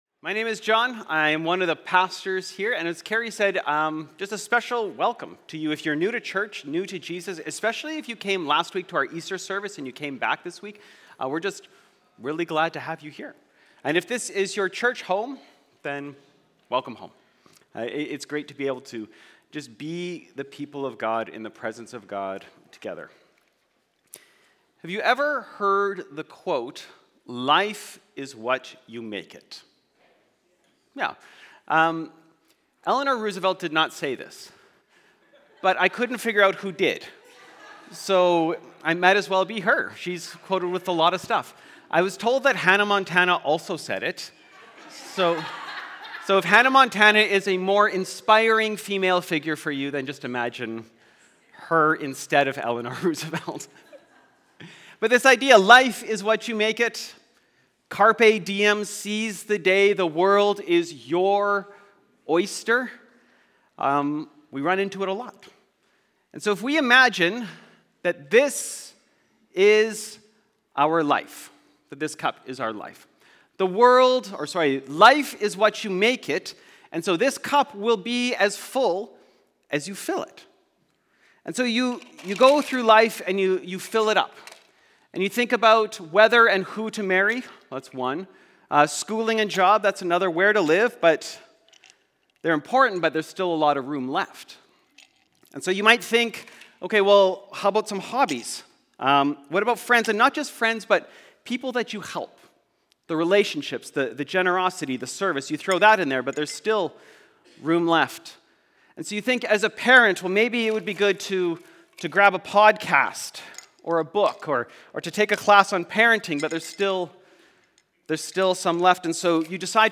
Aldergrove Sermons | North Langley Community Church